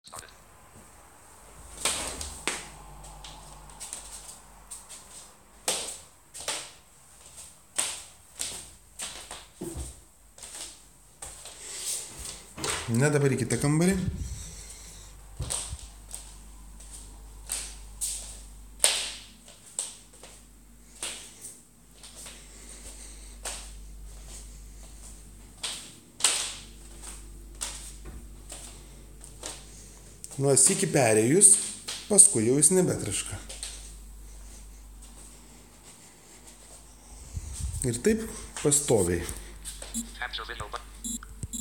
Tema: Re: traskantis alloc laminatas.
ryte kaiptik atsikeles irecordinau, kai dar niekas nevaiksciojo ant jo.